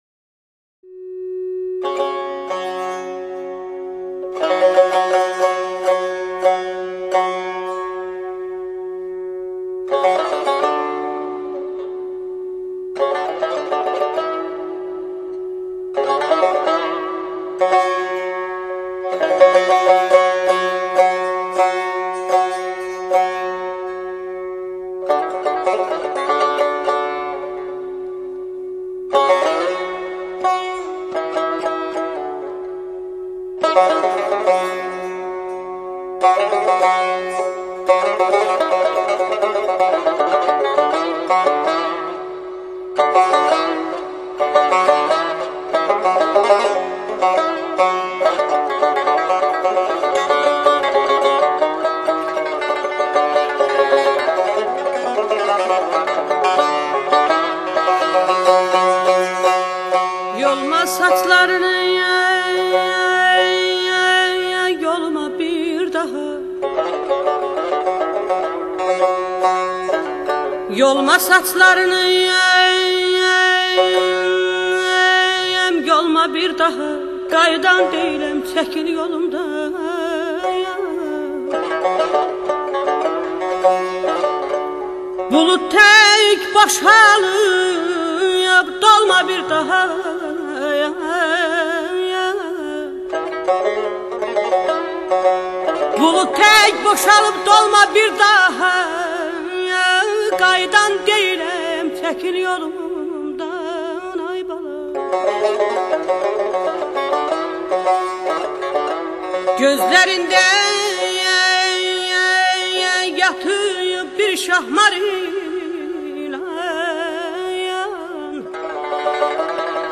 اهنگ ترکی